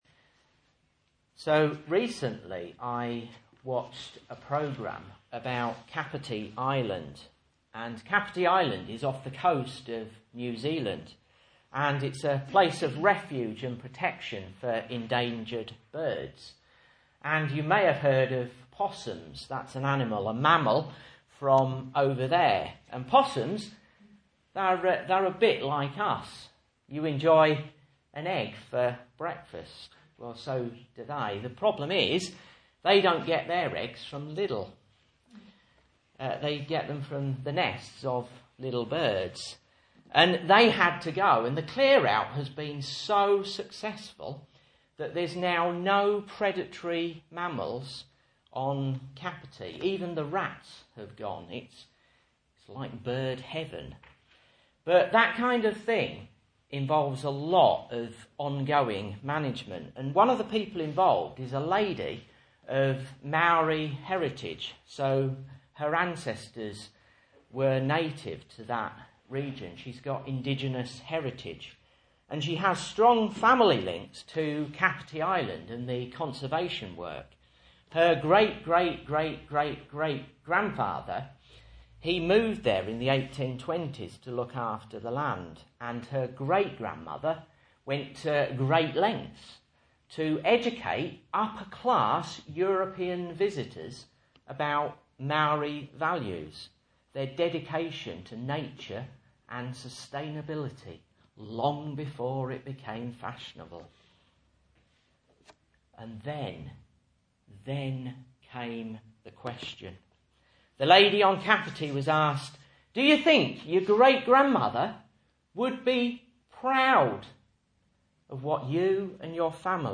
Message Scripture: Philippians 2:19-30 | Listen